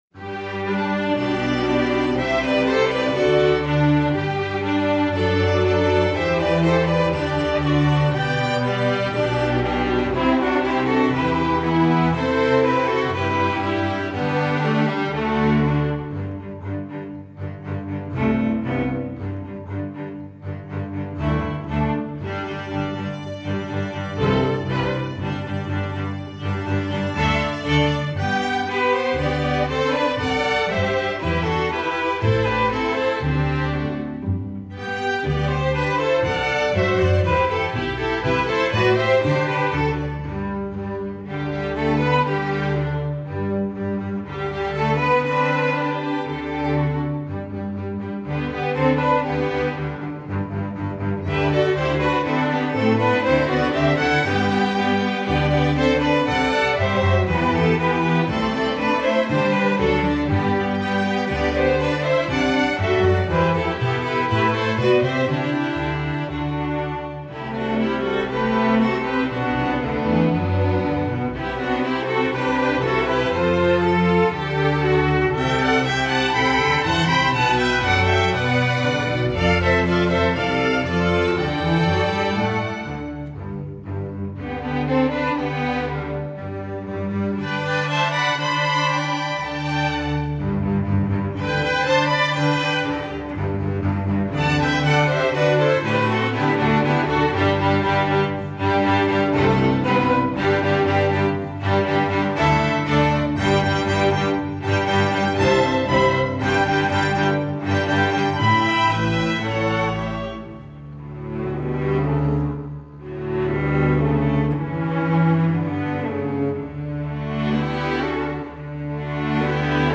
Instrumentation: string orchestra
patriotic, festival